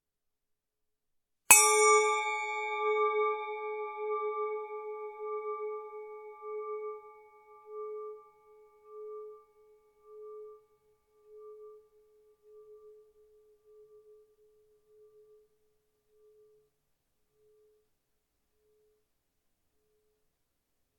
GobletStruck1LHR
bell chime cup ding dong goblet metal ping sound effect free sound royalty free Sound Effects